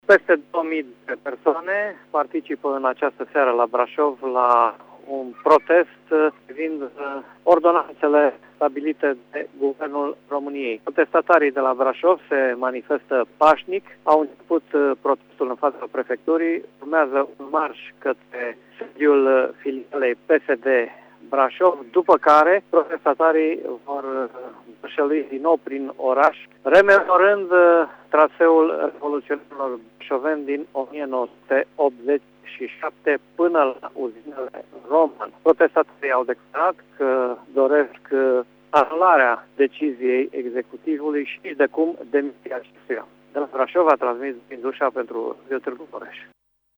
Vine cu detalii, de la Brașov